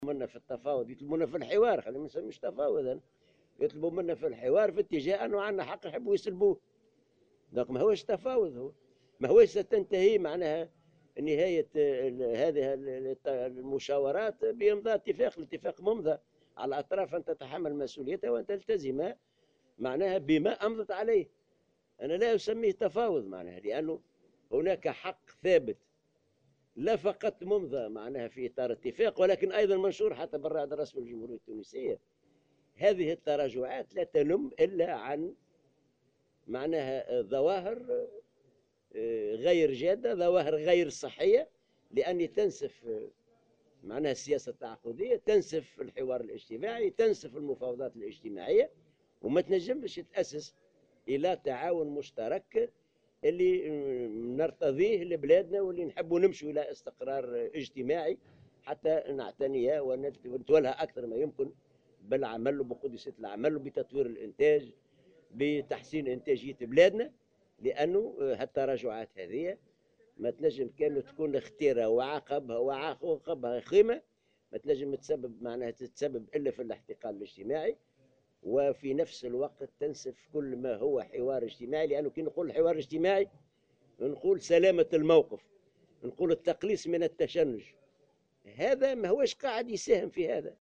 ودعا العباسي في تصريح لمراسل "الجوهرة أف أم" منظمة الأعراف إلى احترام الاتفاقيات، والالتزام بما أمضت عليه، مشيرا إلى أن الانطلاق في المفاوضات للزيادة في أجور القطاع الخاص سيتم حسب الاتفاق المبرم.